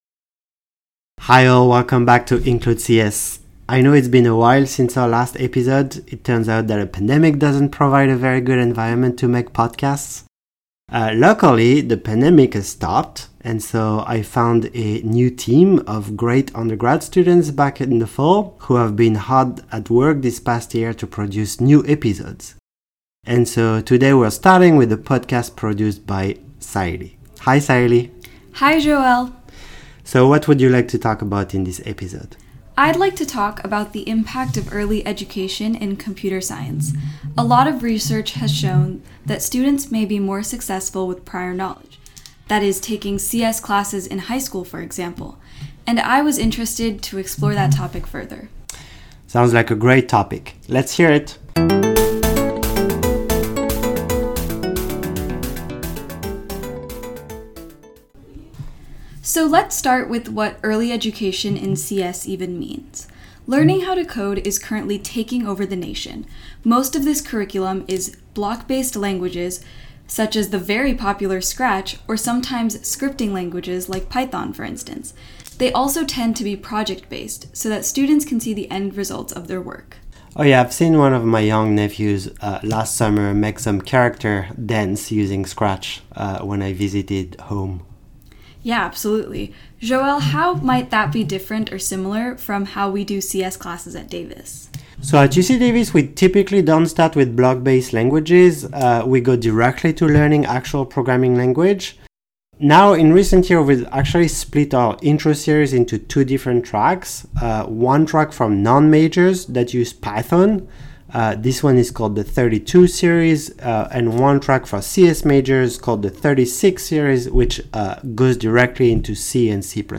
Research shows that early CS education may help CS students perform better in college. In this episode, we explore this question via interviews with college students and college professors.
We also talk with multiple CS majors, some who had prior CS experience before going to college and some who didn’t.